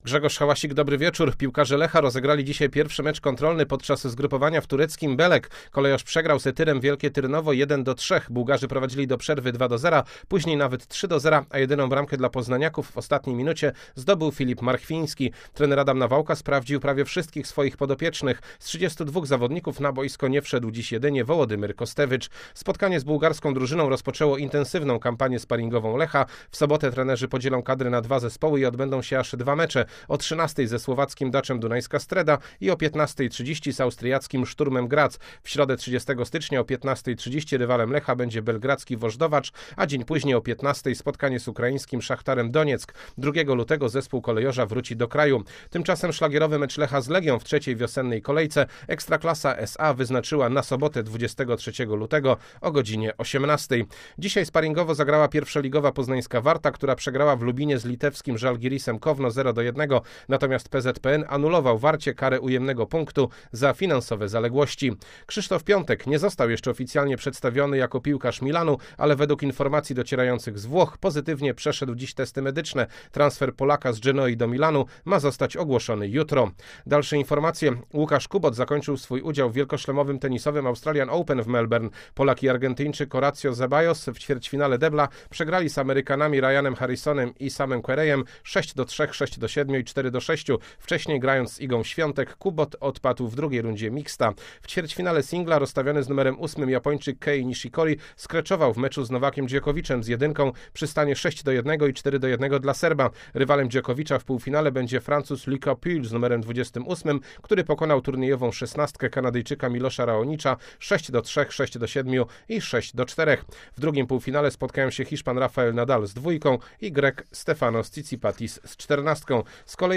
23.01. serwis sportowy godz. 19:05